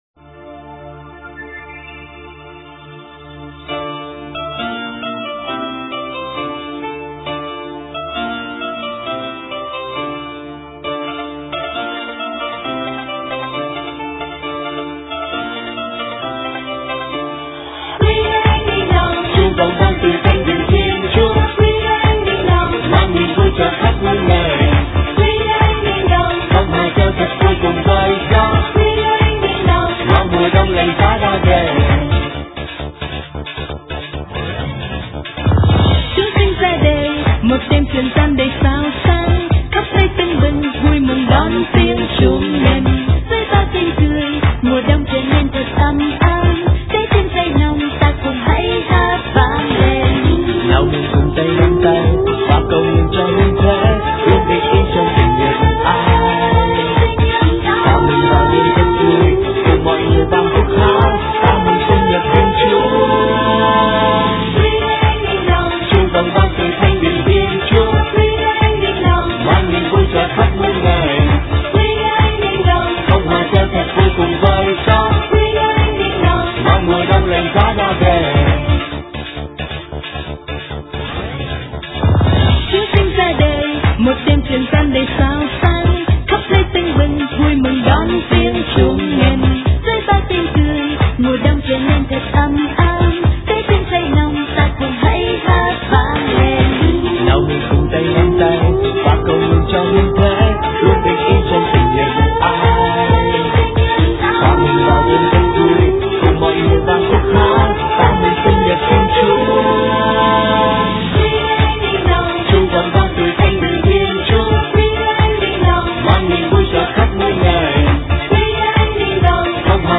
Dòng nhạc : Giáng Sinh